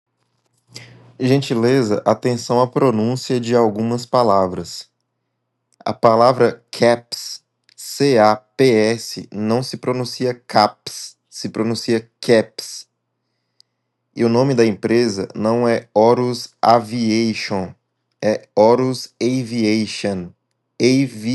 PRECISO DE UMA VOZ IMPACTANTE E GENTIL, NADA EXAGERADO E BEM PROFISSIONAL